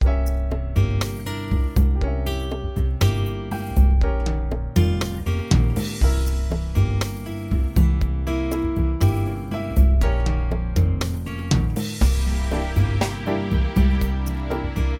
Mixolydian b2 b6 Mode